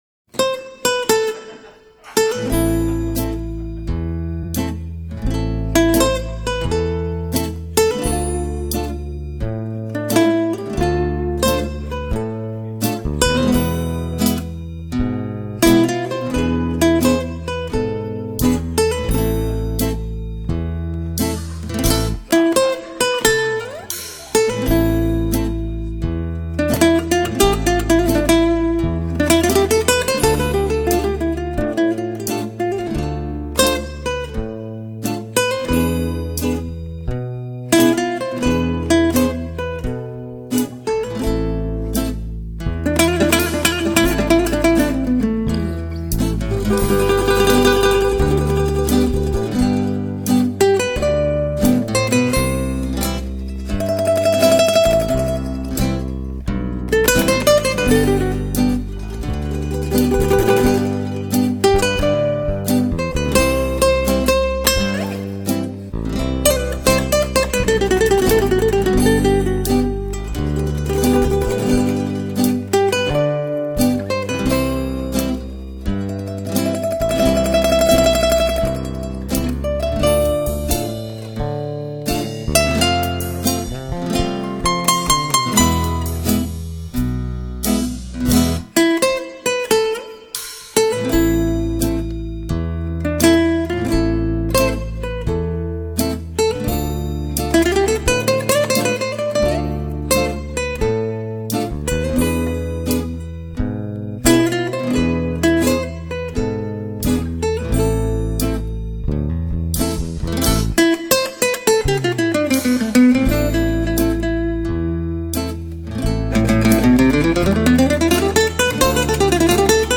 一首非常好听的吉他曲，可惜不知道名字，求名！